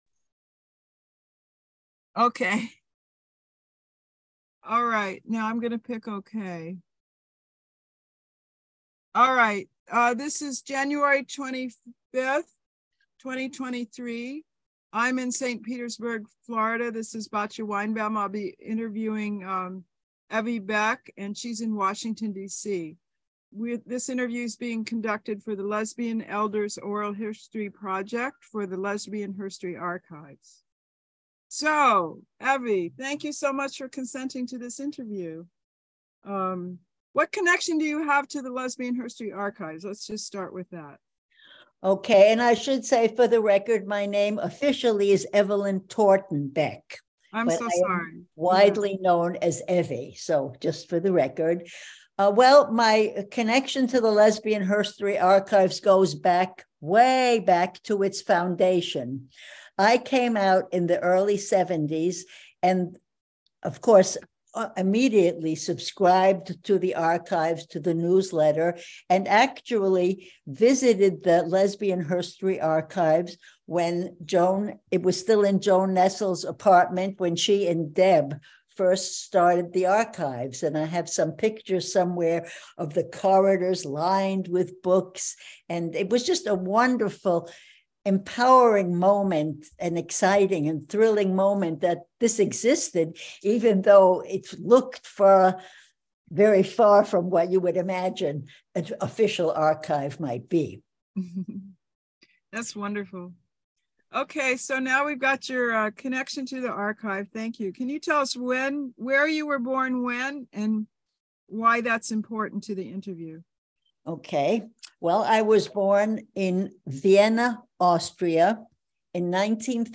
Audio Interview Interviewee